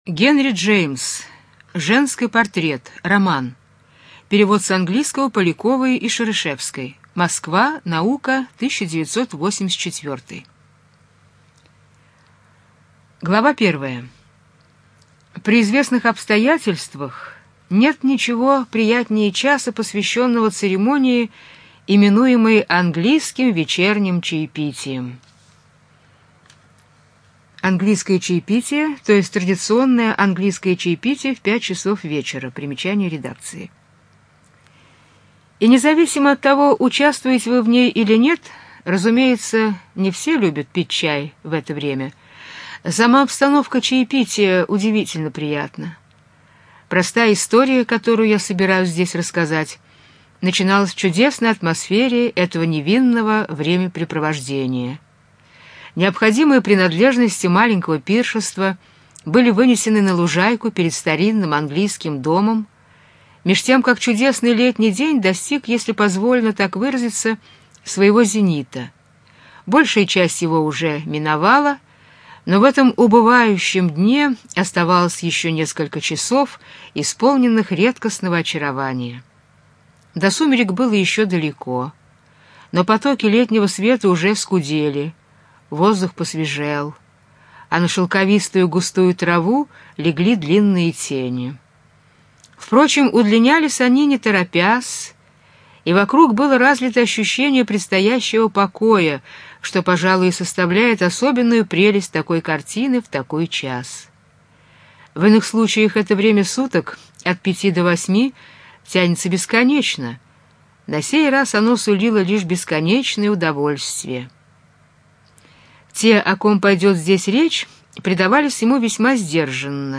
ЖанрКлассическая проза
Студия звукозаписиЛогосвос